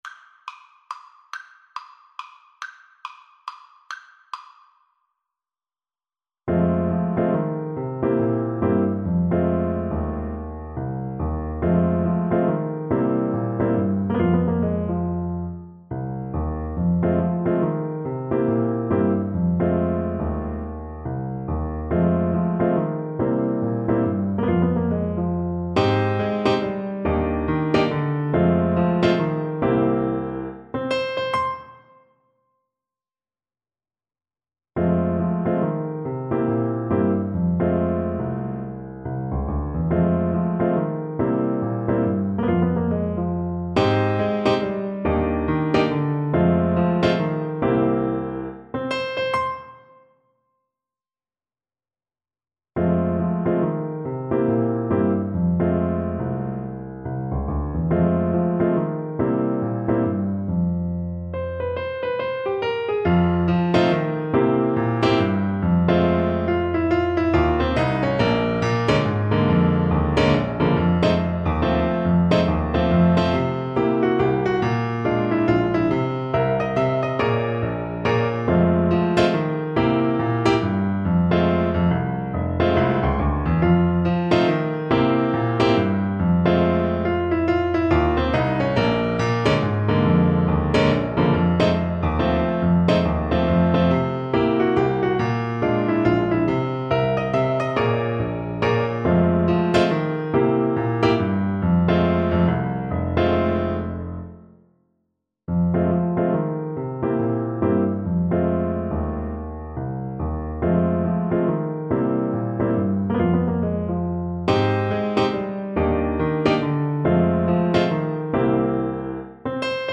Alto Saxophone
=140 Fast swing